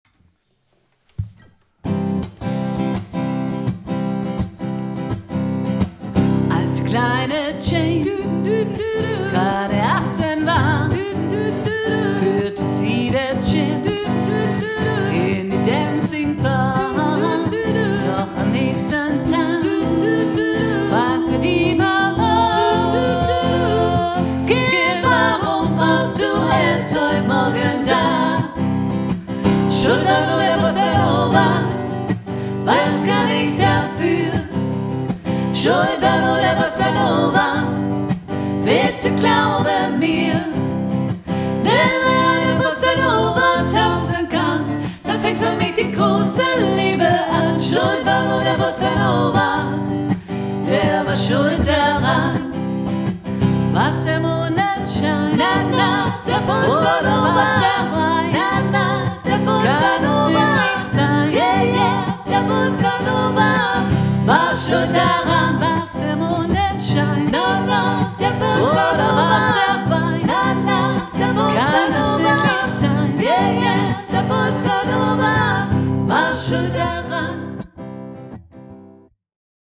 Offenes Singen - ChorArt zwanzigelf - Page 4 | Der moderne Chor in Urbach
Offenes Singen Schuld ist nur der Bossa Nova alle
ChorArt zwanzigelf – das sind junge und jung gebliebene Sängerinnen und Sänger, die nicht nur Spaß in der Probe, sondern auch auf der Bühne haben.
Wir sind laut, leise, kraftvoll, dynamisch, frisch, modern, bunt gemischt und alles, nur nicht langweilig!